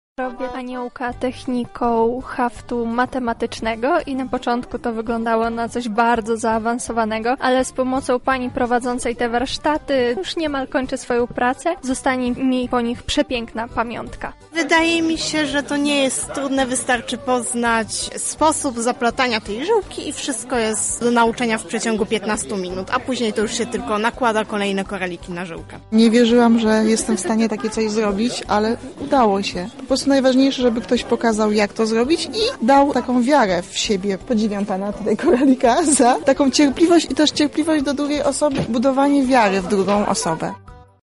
O wrażenia z zajęć zapytała nasza reporterka: